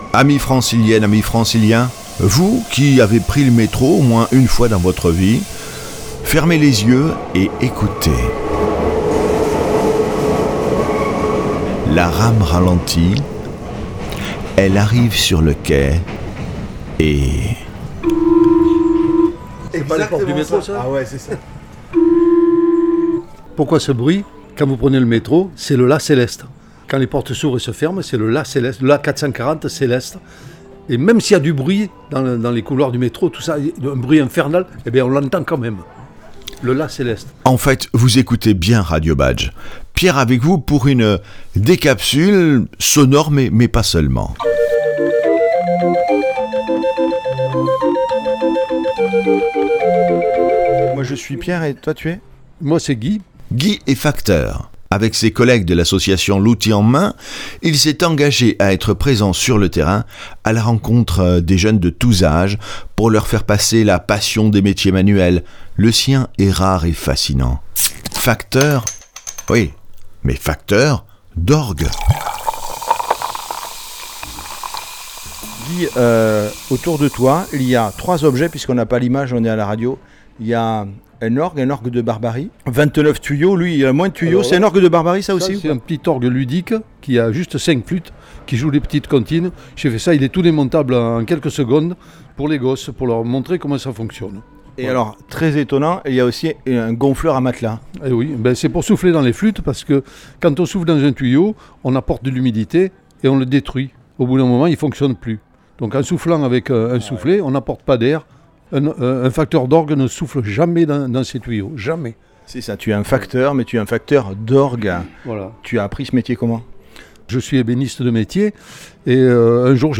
Rencontre sur un stand où figure quelques orgues, un gonfleur à matelas, un moteur de mobilettes et ... un drôle de sifflet.